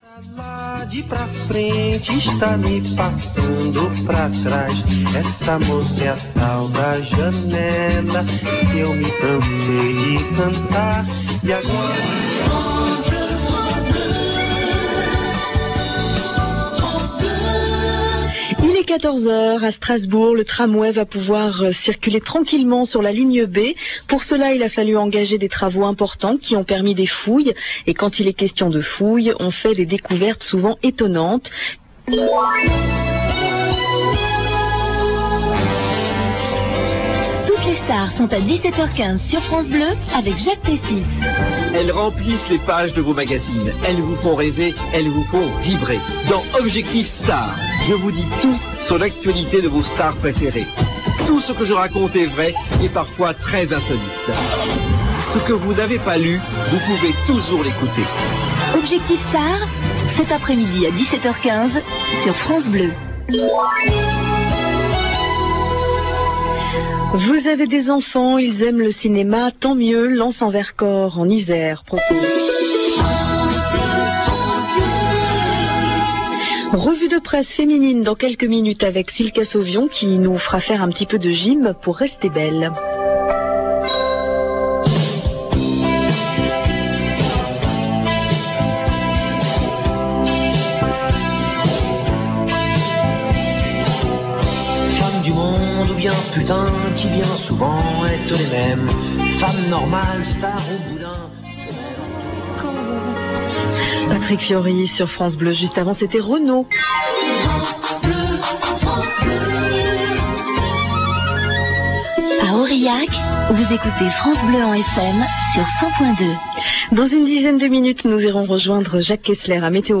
OM-MW 864 kHz - 300 kW
Extraits de R. BLEUE/FRANCE BLEU en AM STEREO,
Récepteur utilisé : SONY SRF-42